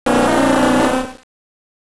サウンド素材「ポケモン鳴き声」